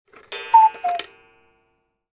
• Качество: 320, Stereo
без слов
тиканье часов